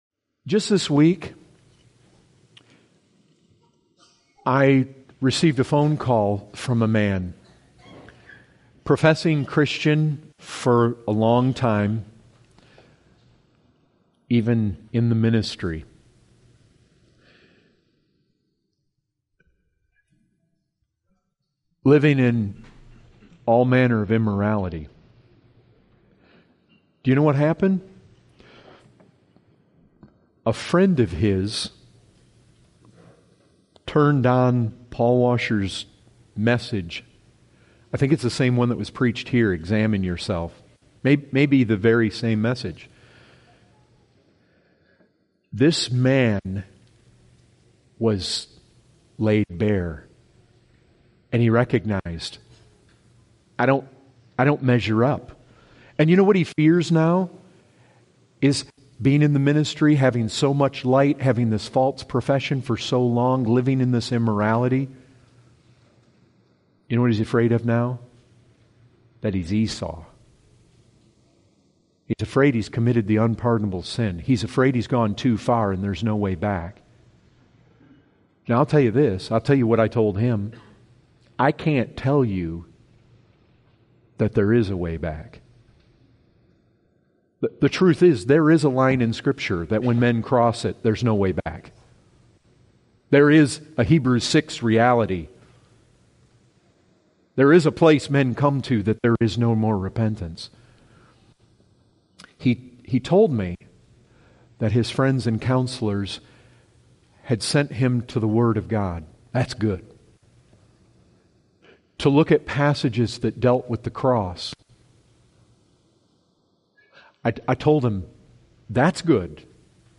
Excerpt taken from the full sermon, “People of Promise“.